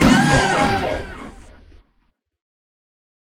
PixelPerfectionCE/assets/minecraft/sounds/mob/horse/zombie/hit1.ogg at mc116